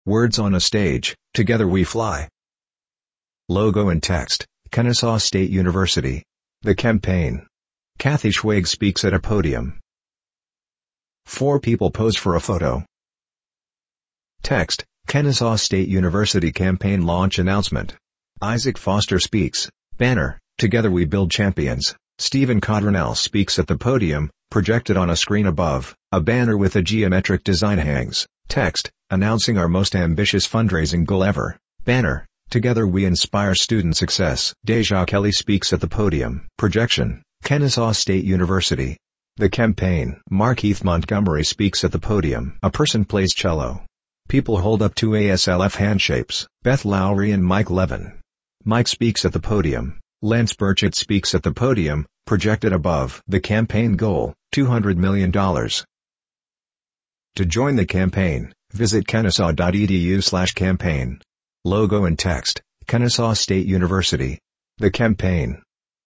Audio Description - The Campaign
audio-description-campaign-video.mp3